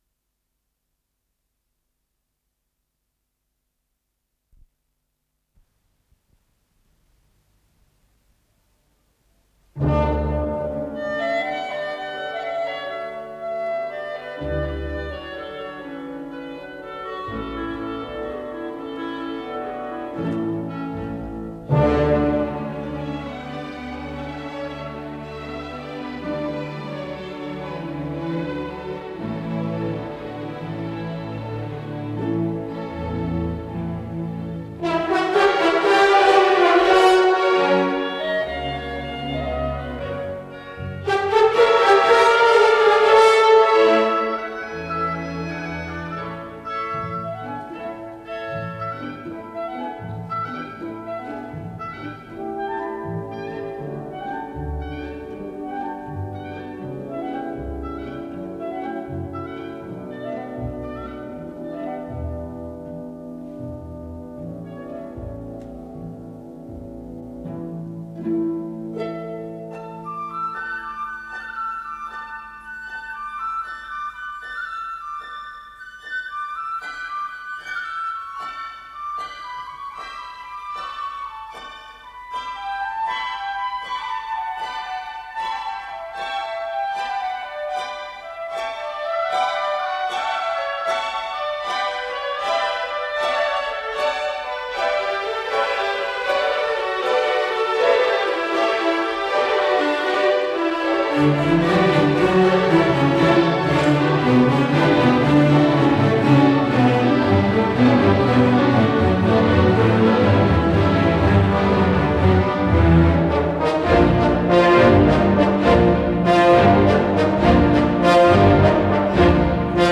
с профессиональной магнитной ленты
ИсполнителиБольшой симфонический оркестр Всесоюзного радио и Центрального телевидения
Художественный руководитель и дирижёр - Геннадий Рождественский